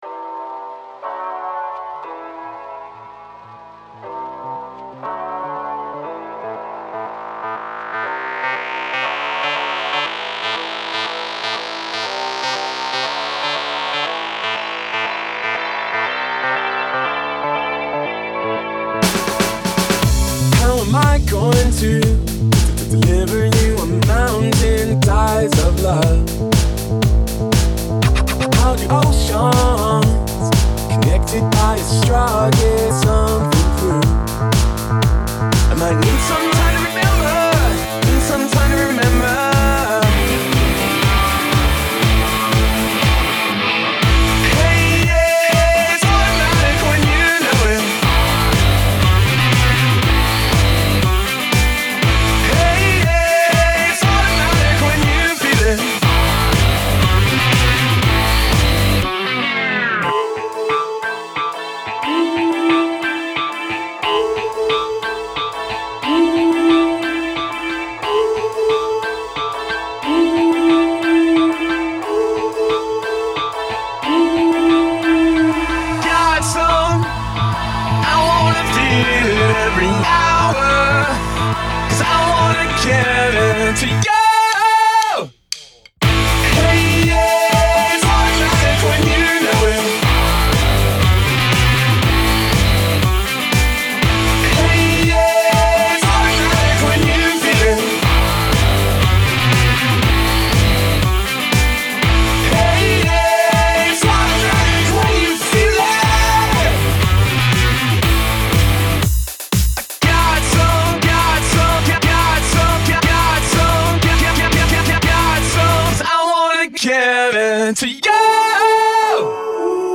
BPM90-120
Audio QualityMusic Cut